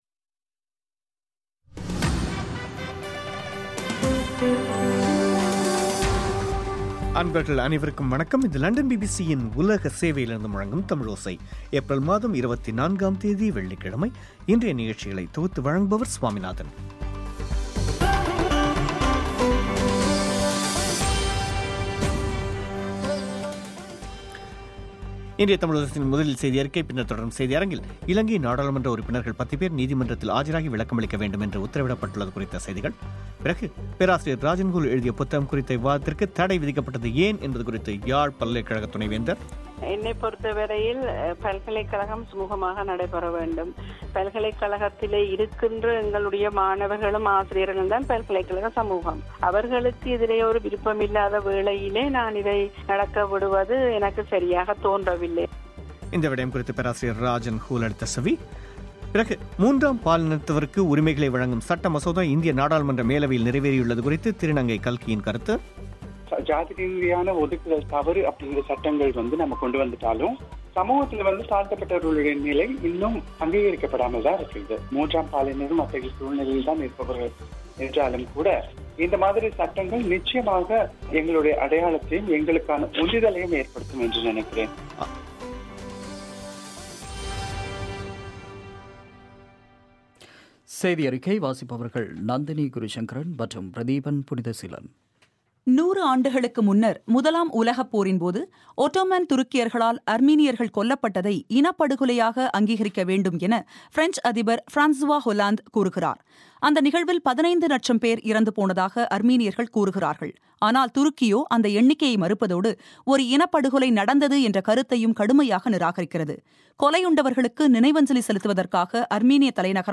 முக்கியச் செய்திகள்